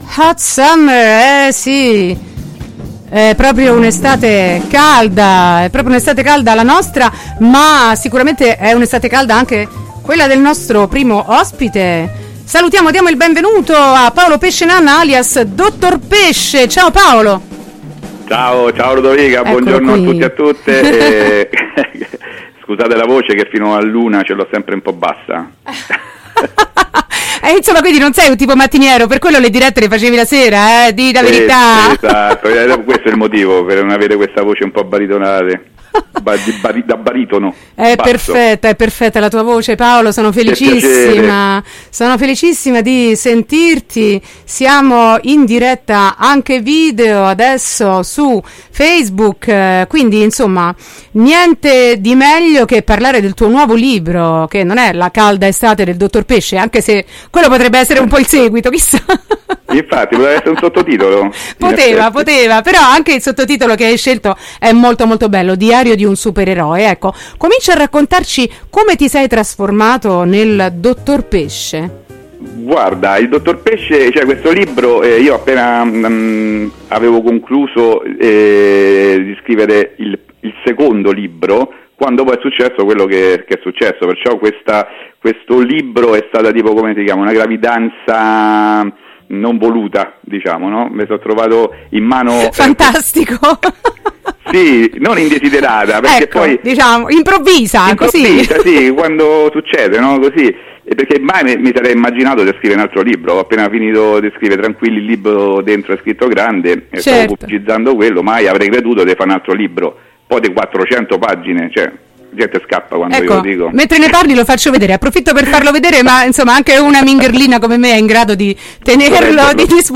Un supereroe in tempo di pandemia – Intervista